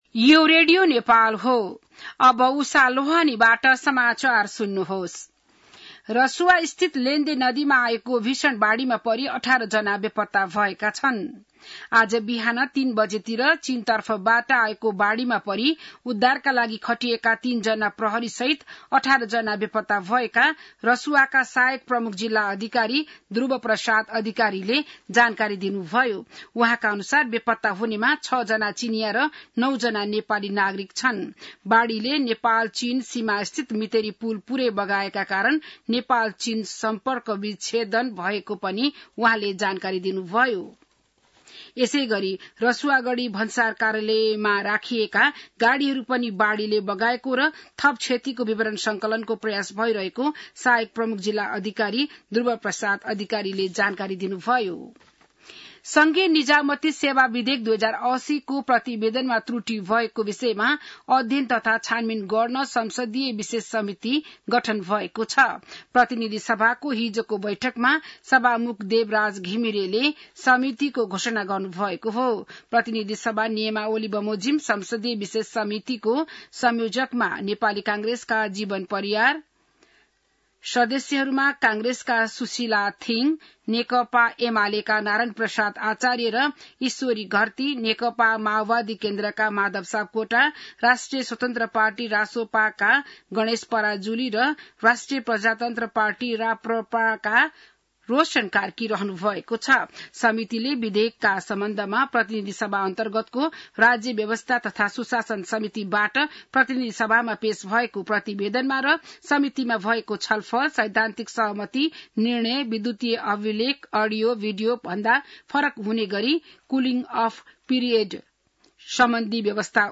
An online outlet of Nepal's national radio broadcaster
बिहान १० बजेको नेपाली समाचार : २४ असार , २०८२